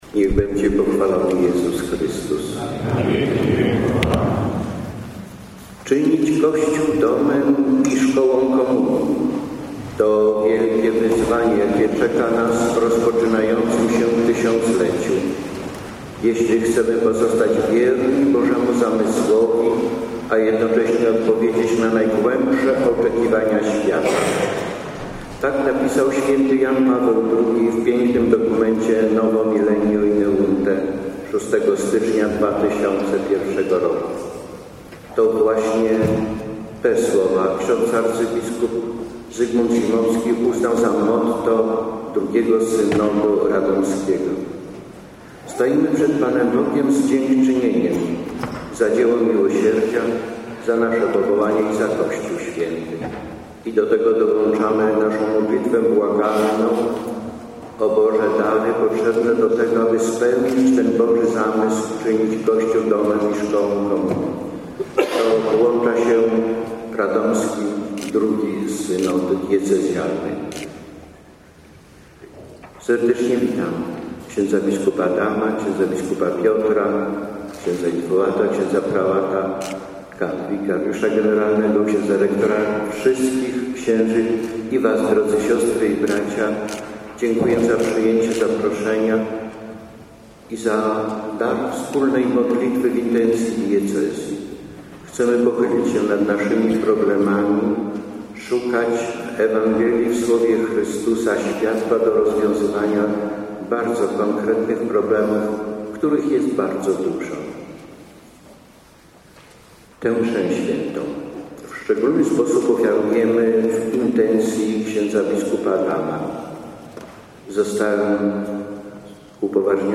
Pierwszą częścią sesji była Msza św. w kaplicy seminaryjnej.
Bp Henryk Tomasik, wprowadzenie: